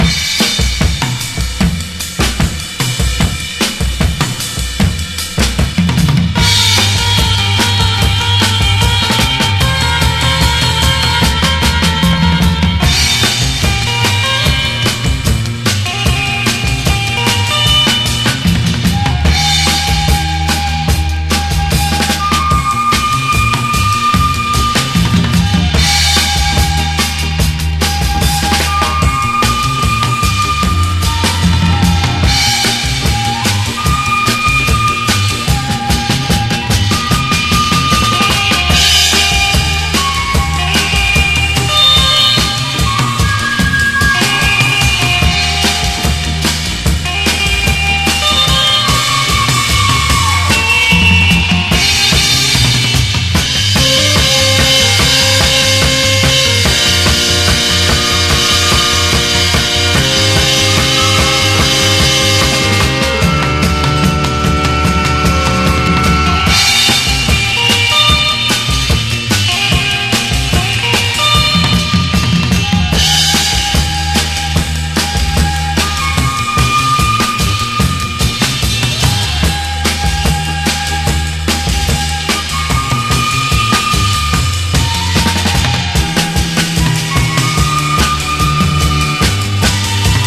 JAPANESE GROOVE
JAPANESE JAZZ
JAZZ FUNK / JAZZ ROCK
ヘヴィー・ヒッティングなドラムの強力なグルーヴィー・カヴァー！
ファンキーに打ちまくってます！